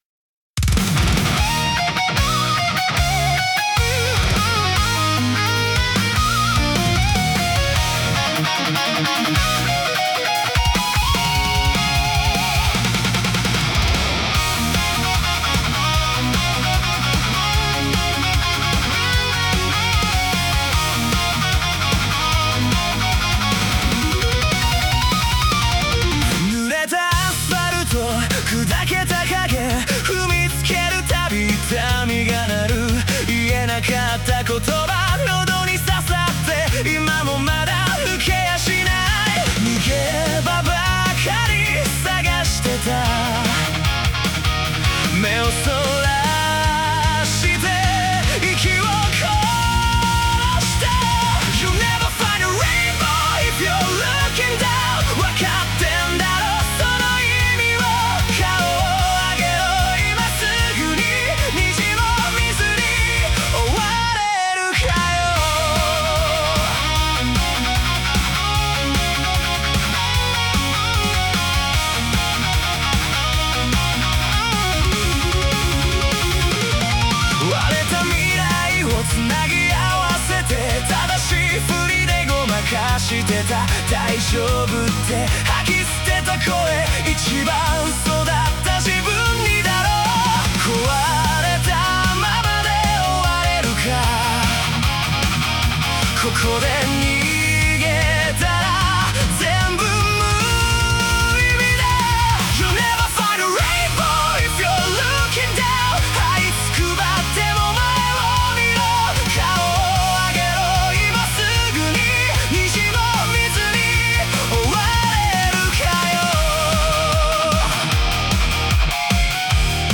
男性ボーカル